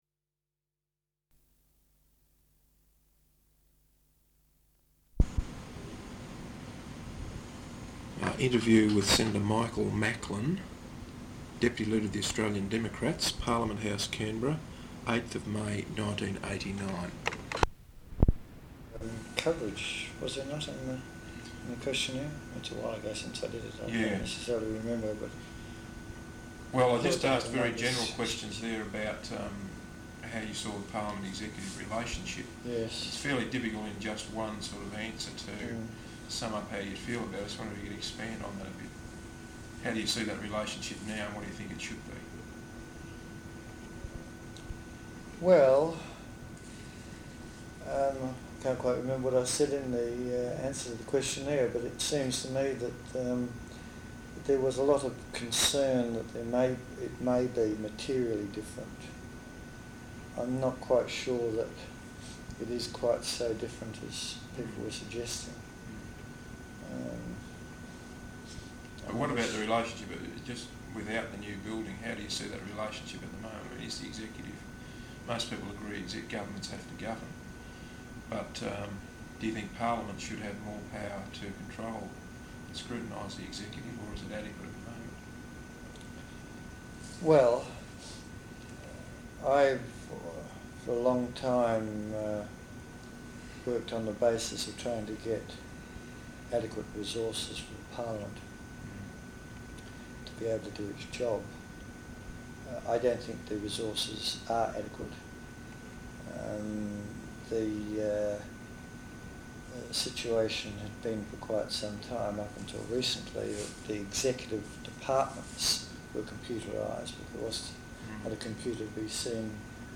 Interview with Senator Michael Macklin, Deputy Leader of the Australian Democrats, Parliament House, Canberra, 8th May 1989.